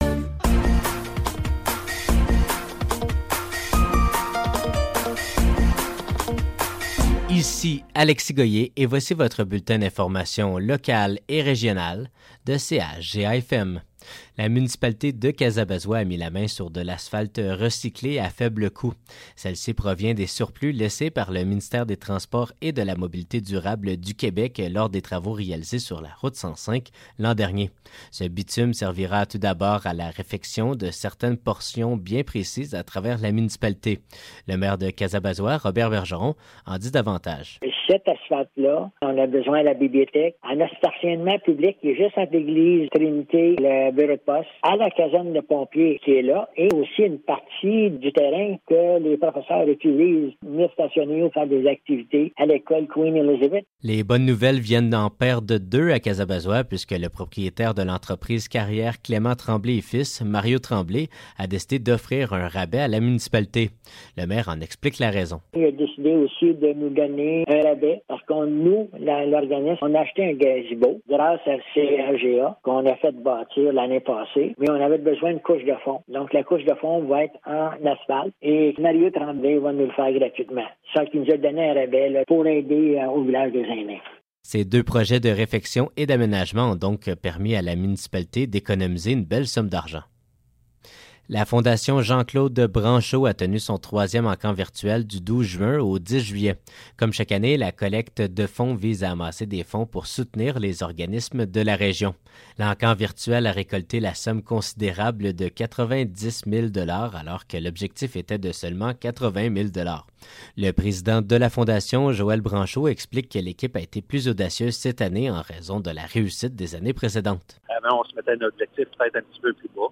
Nouvelles locales - 18 juillet 2024 - 10 h